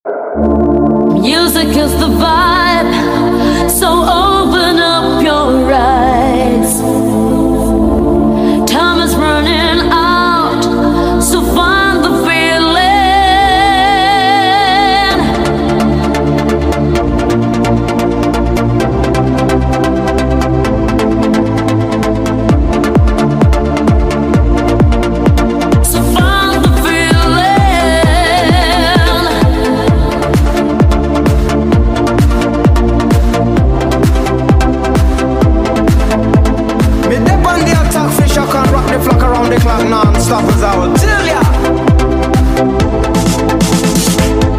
Abbiamo installato doppio amplificatore audiosystem ad 850 per il Subwoofer AUDIOSYSTEM ASY12 ed amplificatore audiosystem as300.2 per il fronte.